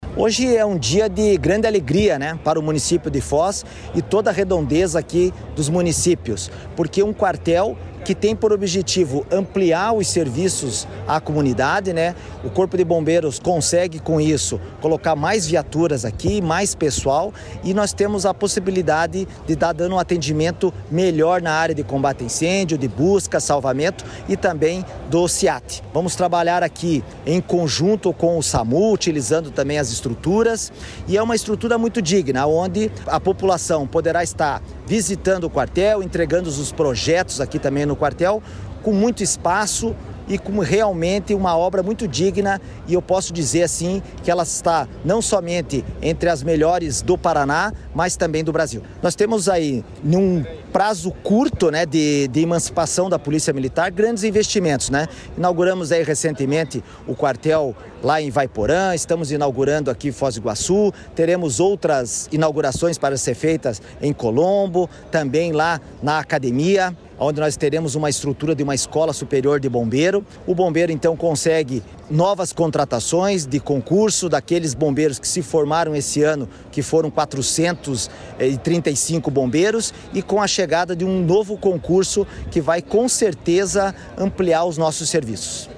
Sonora do comandante-geral do Corpo de Bombeiros Militar do Paraná, coronel Manoel Vasco de Figueiredo Junior, sobre a inauguração da nova sede do Corpo de Bombeiros de Foz do Iguaçu